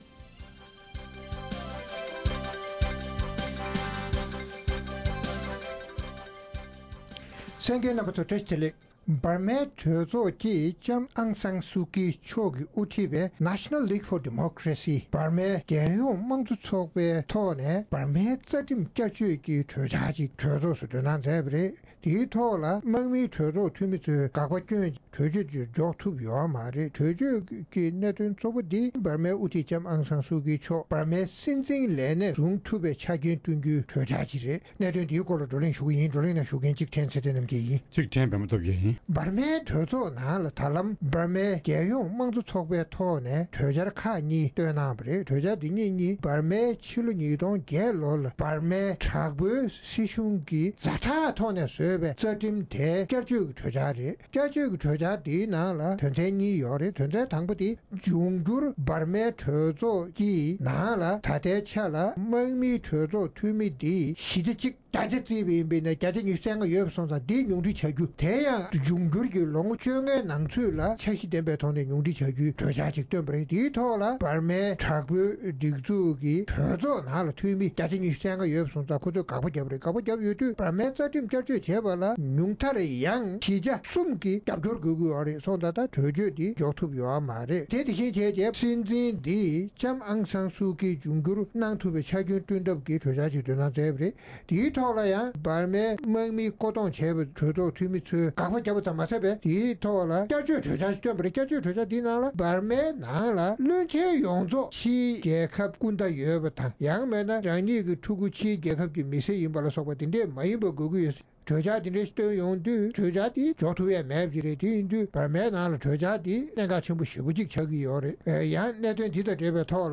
འབར་མ་འམ་Myanmarཞེས་པའི་ཡུལ་གྱི་དབུ་ཁྲིད་ལྕམ་Aung Sang Suu Kyi ཡིས་དབུ་ཁྲིད་པའི་རྒྱལ་ཡོངས་མང་གཙོ་ཚོགས་པས་སྲིད་འཛིན་འོས་ཆོས་དང་། དམག་མིའི་སྒྲིག་འཛུགས་ཀྱི་བསྐོ་གཞག་བྱས་པའི་གྲོས་ཚོགས་འཐུས་མི་ཉུང་འཁྲི་བྱ་རྒྱུའི་རྩ་ཁྲིམས་བསྐྱར་བཅོས་ཀྱི་གྲོས་འཆར་ལ་དམག་མིའི་སྒྲིག་འཛུགས་ཀྱི་གྲོས་ཚོགས་འཐུས་མིས་འགག་པ་བརྒྱབ་སྟེ་རྩ་ཁྲིམས་བསྐྱར་བཅོས་གནང་ཐུབ་མེད་པའི་སྐོར་རྩོམ་སྒྲིག་འགན་འཛིན་གཉིས་ཀྱིས་བགྲོ་གླེང་གནང་བ་གསན་རོགས་གནང་།།